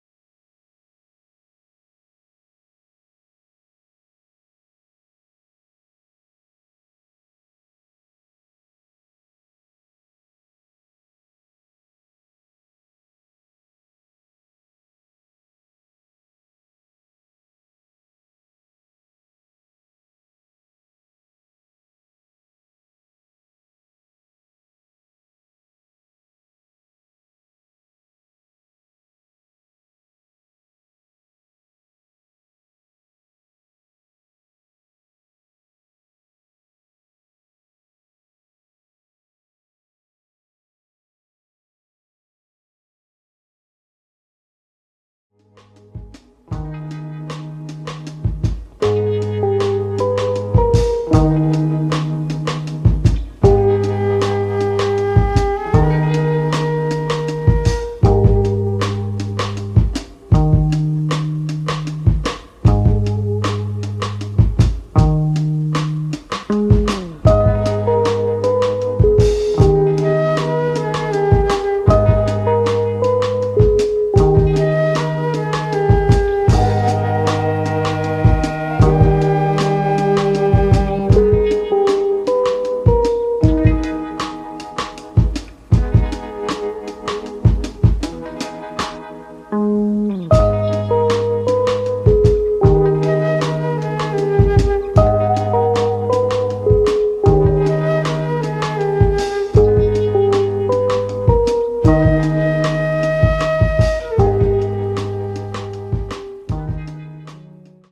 Experimental vibes.